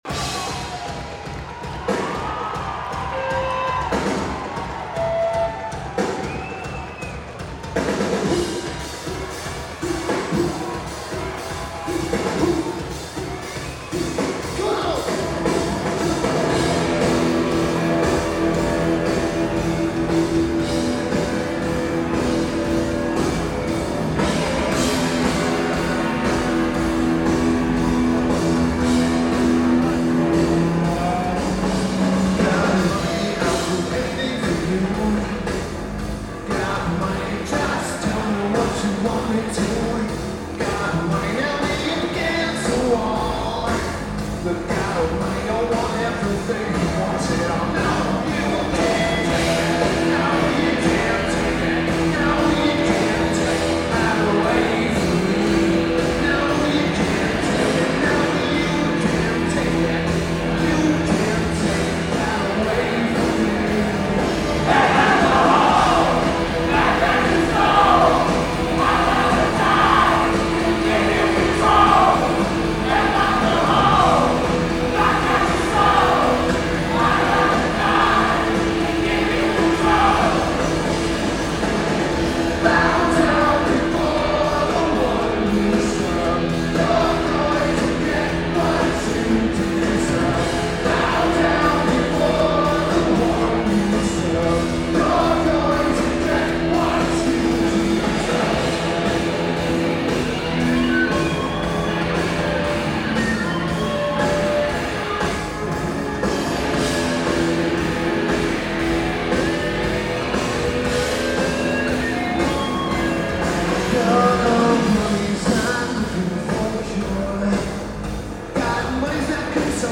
Brixton Academy
Lineage: Audio - AUD (Sony ECM-909a + Sony TCD-D8)